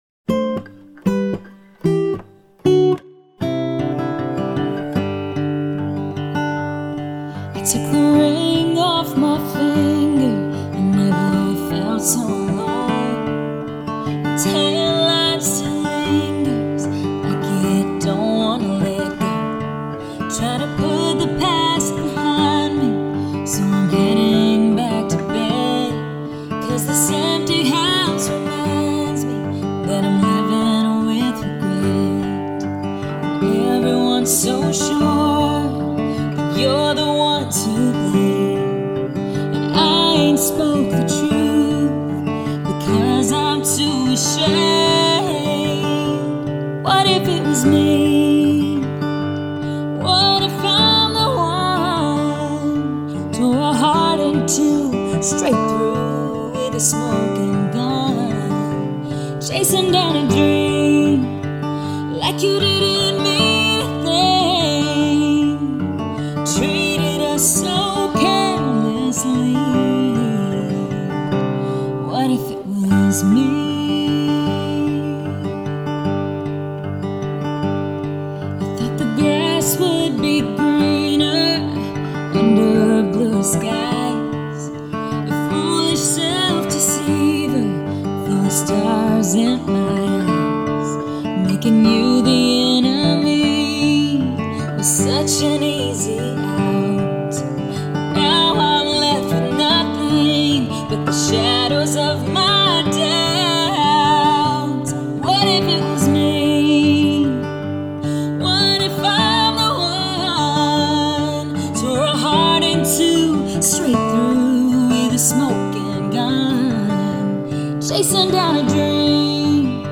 Here is the final studio demo of the song
Why This Demo Works (Even Without a Full Band)
Even though the final demo is a simple guitar/vocal recording
expressive vocals